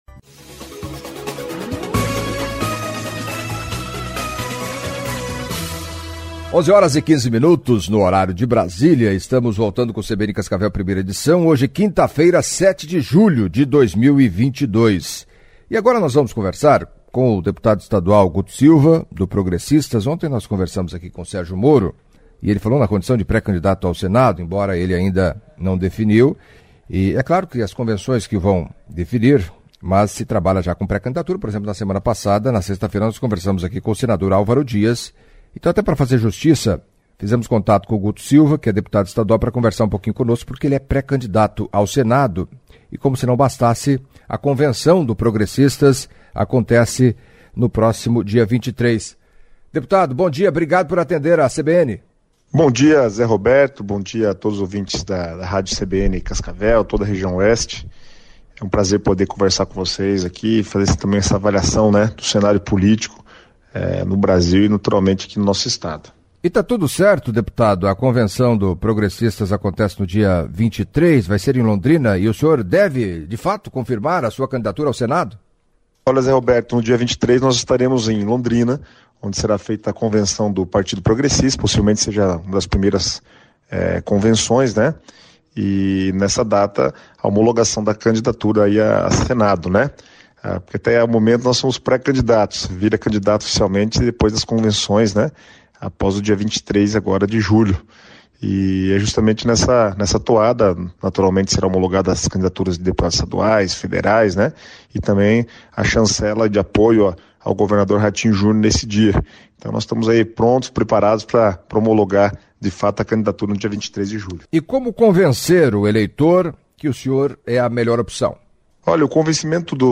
Em entrevista à CBN Cascavel nesta quinta-feira (07) o deputado estadual Guto Silva (PP) anunciou para o próximo dia 23 de julho a convenção partidária. Segundo ele, o Progressistas estará homologando o seu nome como candidato ao Senado.